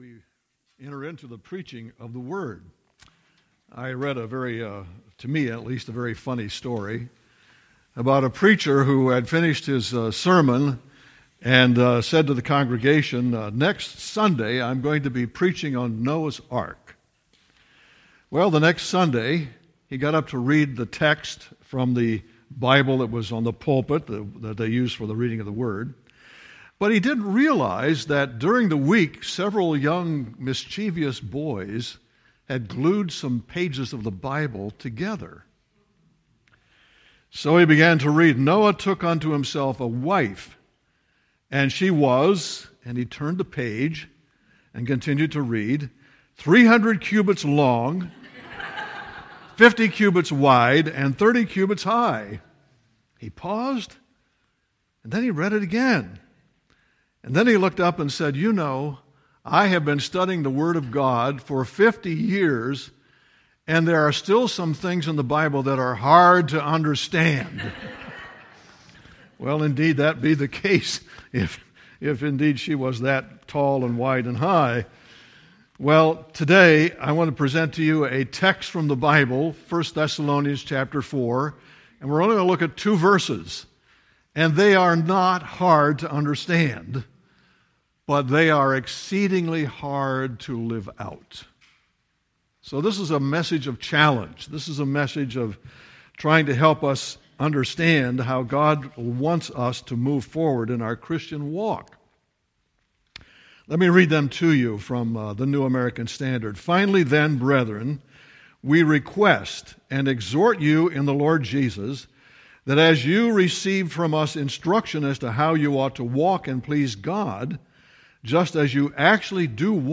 2019 Excel Still More Preacher